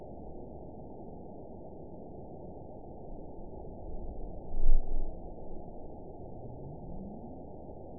event 912199 date 03/21/22 time 00:17:34 GMT (3 years, 2 months ago) score 9.55 location TSS-AB01 detected by nrw target species NRW annotations +NRW Spectrogram: Frequency (kHz) vs. Time (s) audio not available .wav